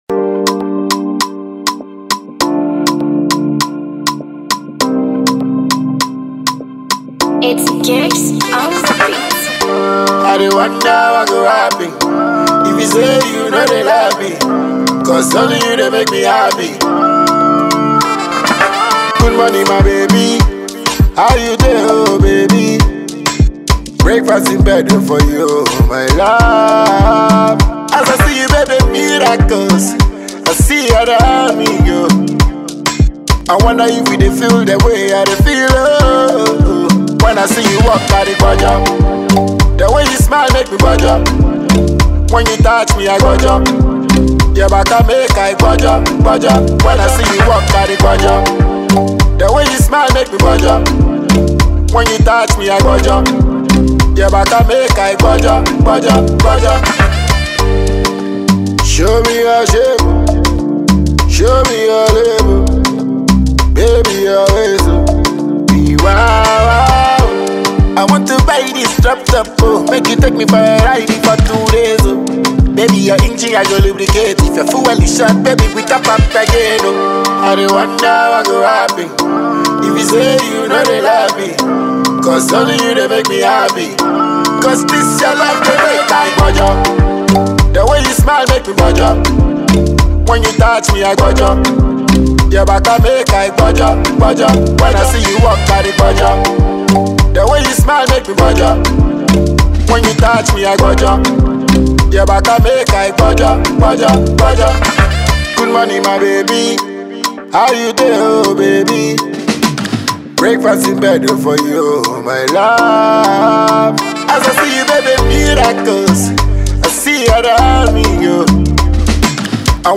Ghanaian dancehall king